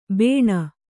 ♪ bēṇa